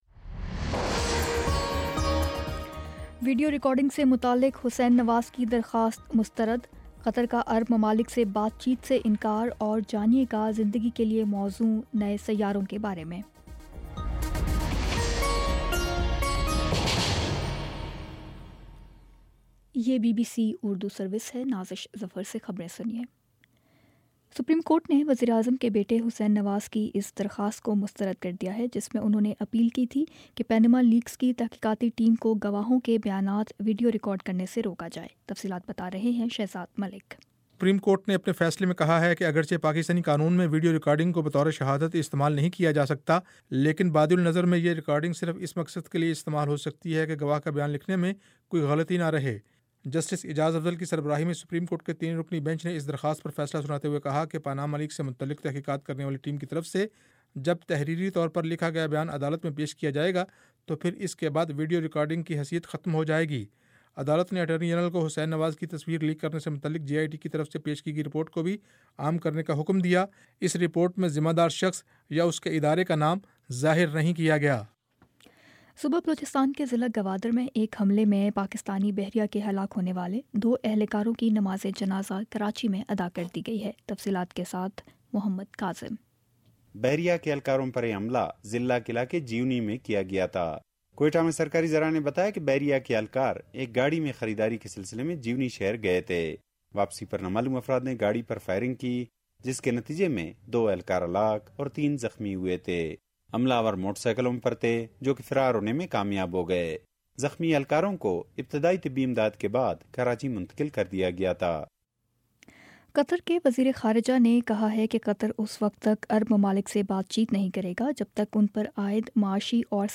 جون 20 : شام چھ بجے کا نیوز بُلیٹن